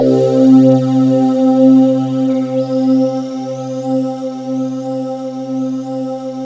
Index of /90_sSampleCDs/USB Soundscan vol.28 - Choir Acoustic & Synth [AKAI] 1CD/Partition D/22-RESOVOXAR